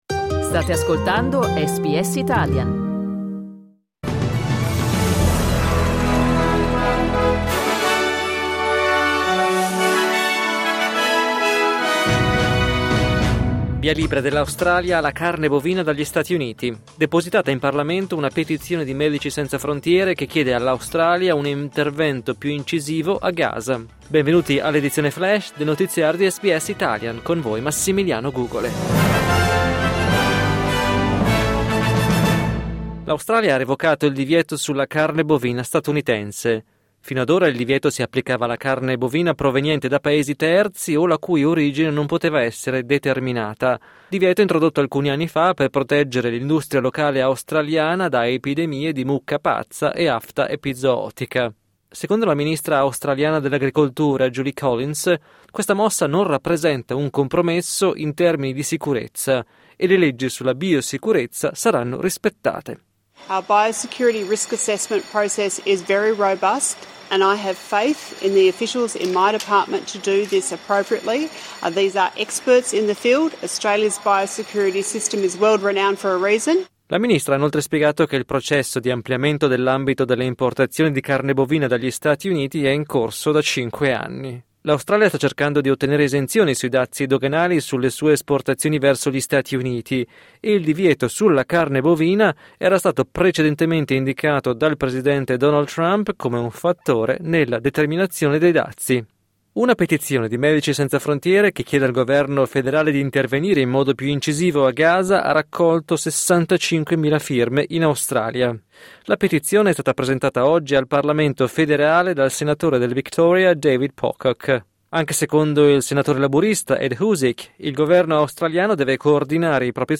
News flash giovedì 24 luglio 2025